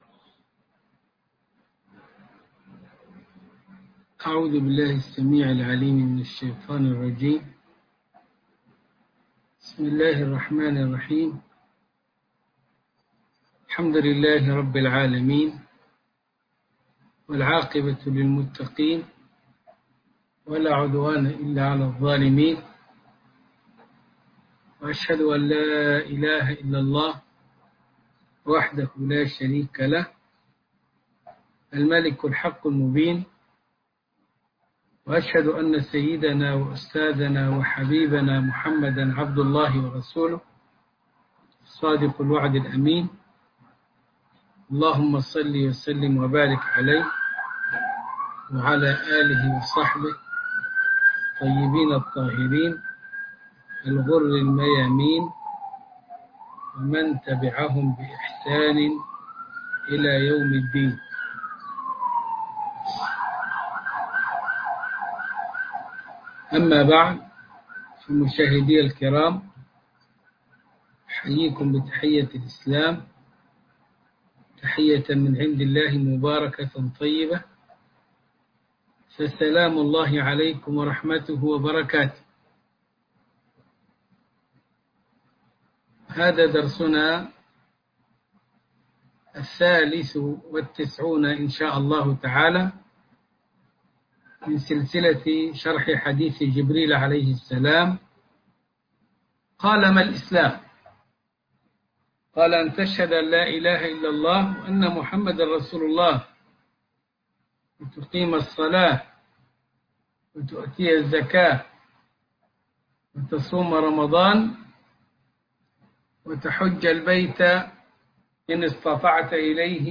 عنوان المادة الدرس ( 93) ( شرح حديث جبريل عن الاسلام والايمان والاحسان) تاريخ التحميل الأحد 28 فبراير 2021 مـ حجم المادة 28.73 ميجا بايت عدد الزيارات 242 زيارة عدد مرات الحفظ 112 مرة إستماع المادة حفظ المادة اضف تعليقك أرسل لصديق